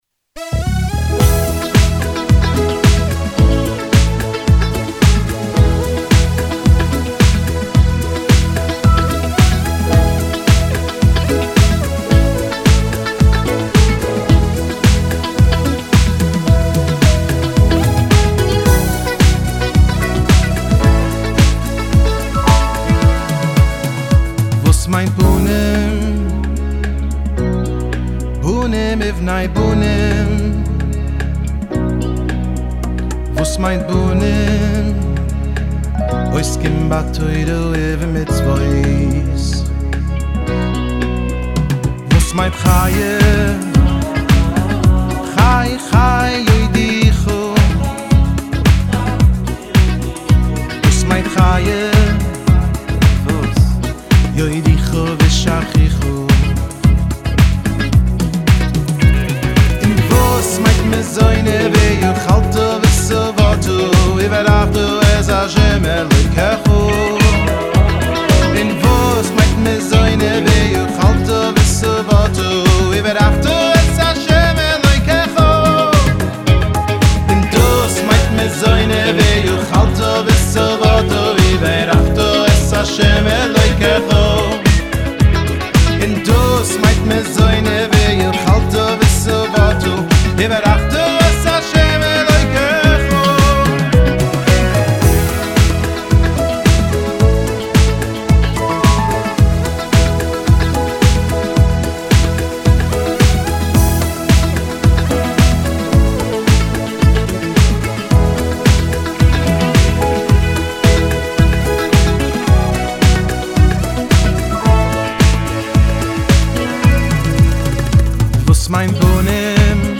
הגיטרות